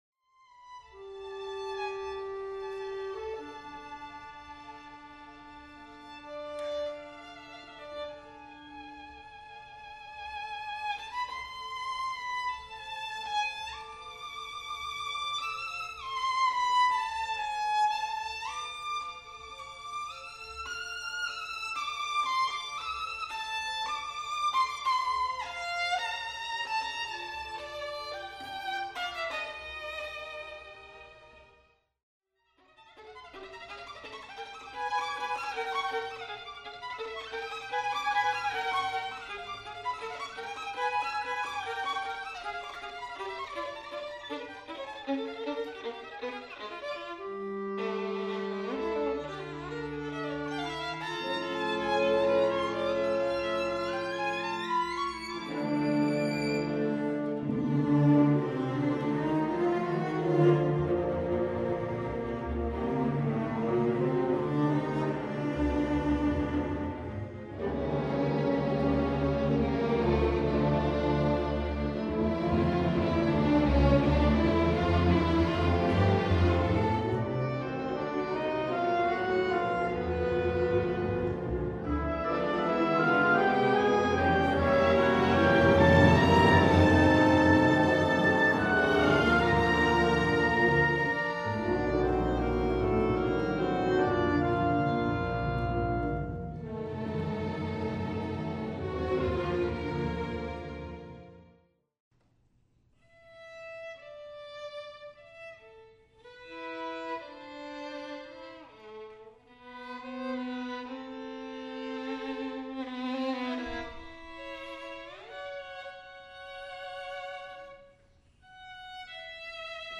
clipart_tech_audio_028* Concerto for Violin and Orchestra in D minor, op. 47 by Jean Sibelius
Violin Soloist
clipart_tech_audio_028* This audio clip is from the dress rehearsal.